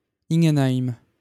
Ingenheim (French pronunciation: [iŋ(ɡ)ənaim]